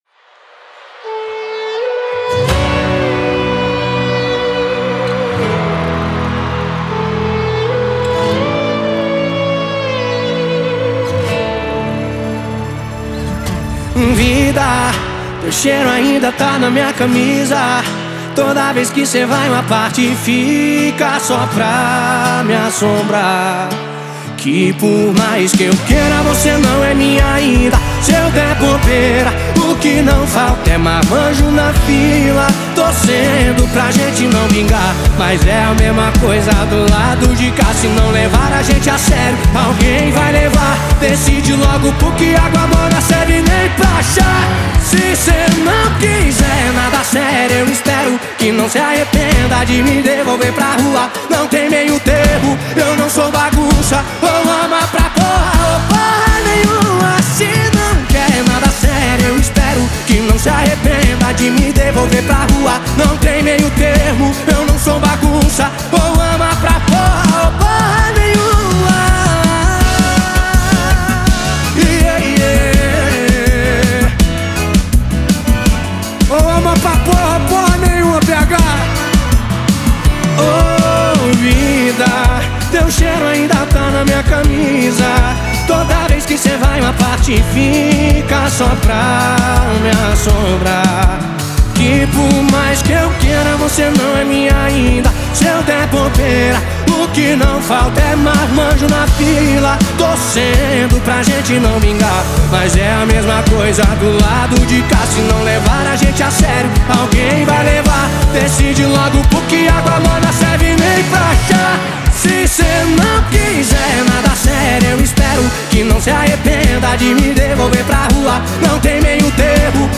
Música – ouvir online e acessar o arquivo